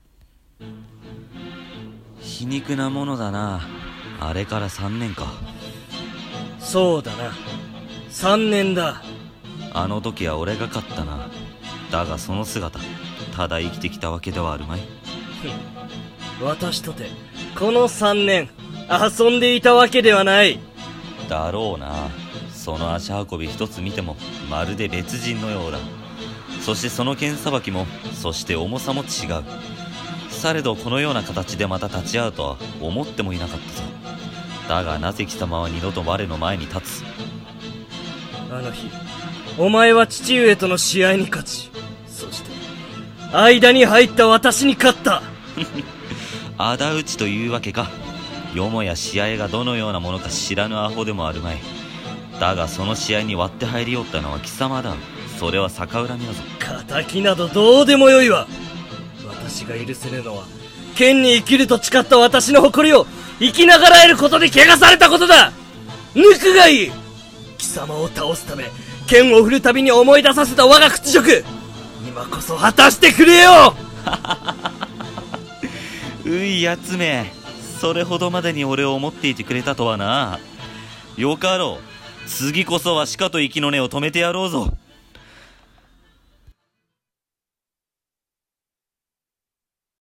【戦闘風】二人の剣客【二人声劇】